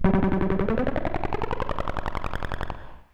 synthFX.wav